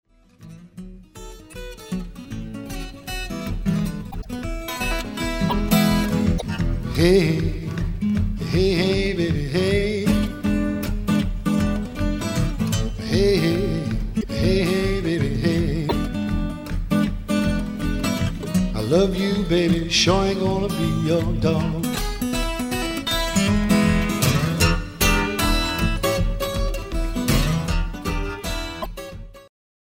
• Welke gitarist, zanger en componist (maar niet van dit nummer) hoort u